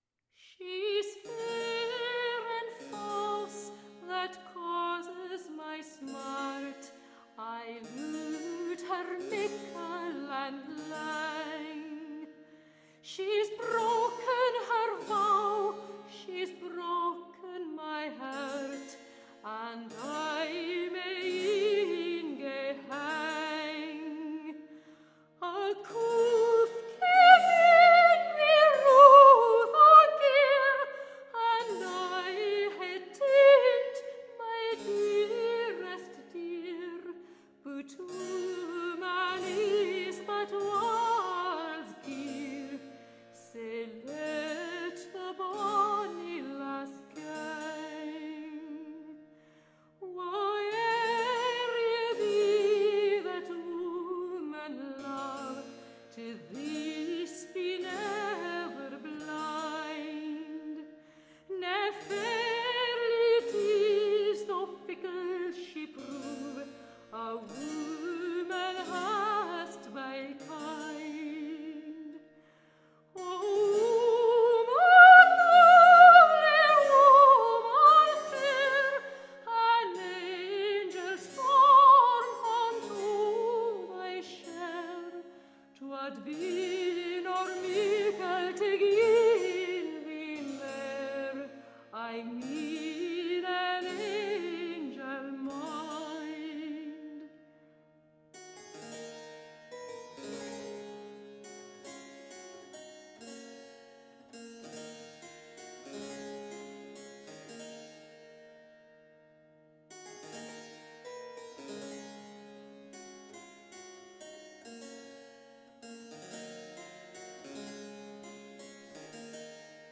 Tagged as: Classical, Folk, Choral, Celtic